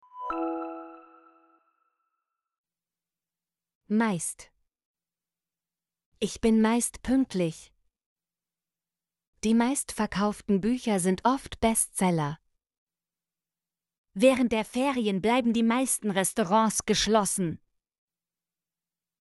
meist - Example Sentences & Pronunciation, German Frequency List